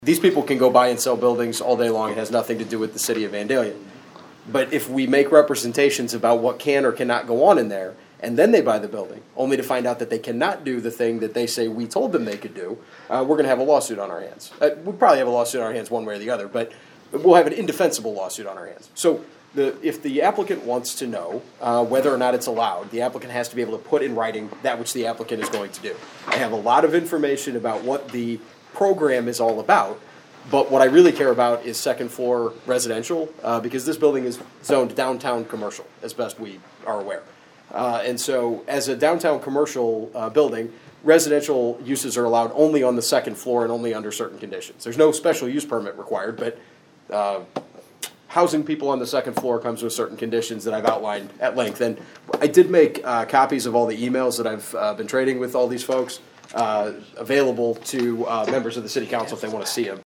There was a long discussion at Monday’s Vandalia City Council meeting on the possible sale of the former First Baptist Church building in downtown Vandalia.